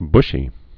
(bshē)